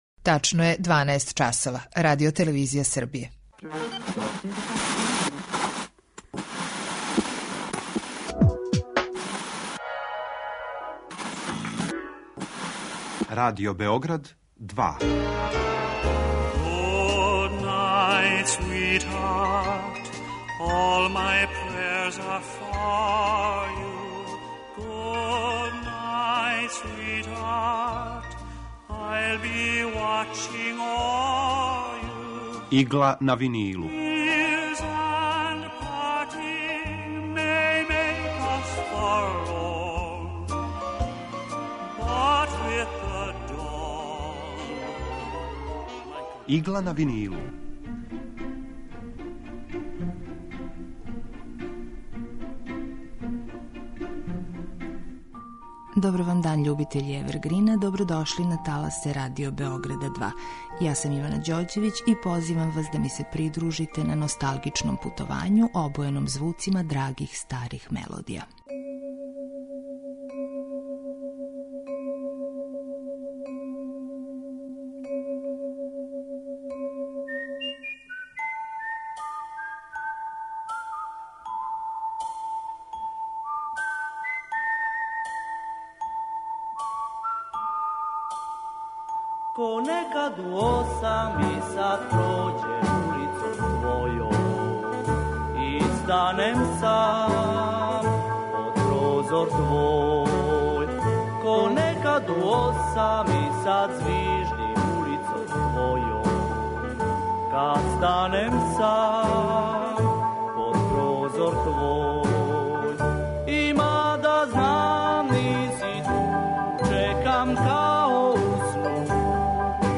Чућете и неке помало заборављене мелодије и нашу сталну рубрику Два лица једног хита.